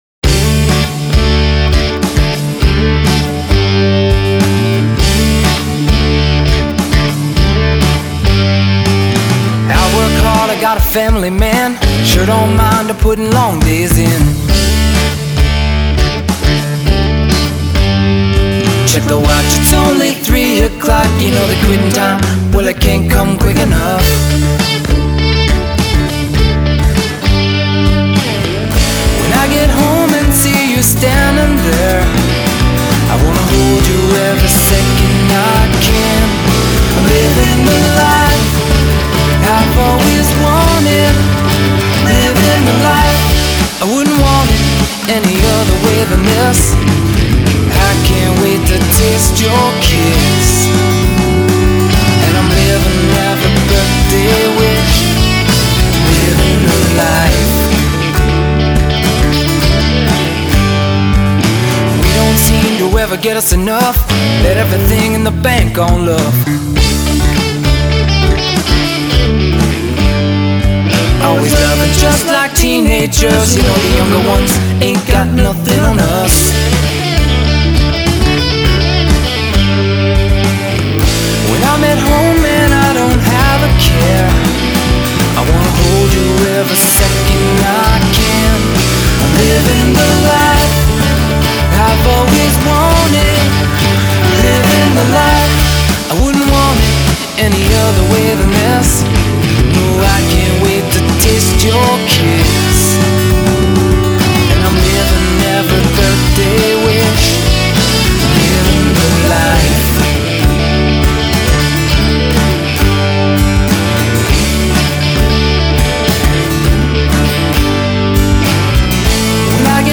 we were able to record this production in both Ontario and Nashville.